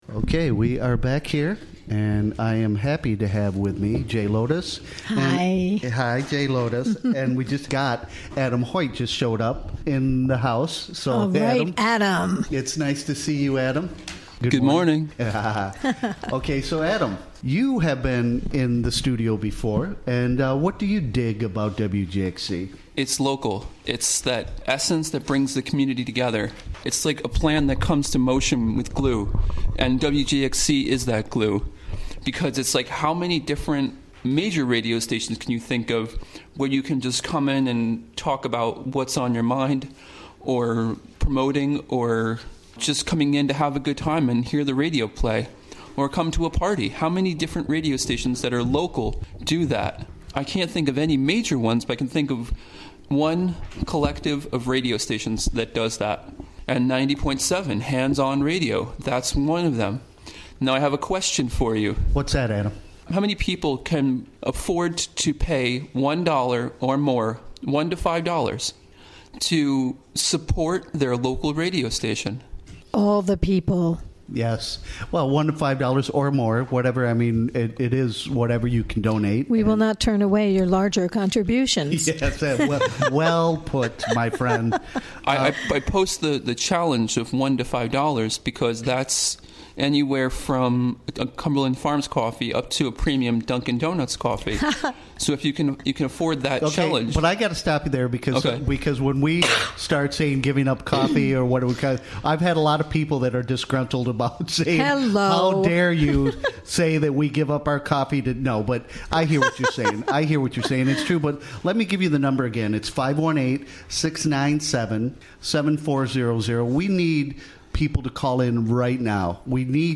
Recorded during the WGXC Morning Show, Tue., Oct. 10, 2017.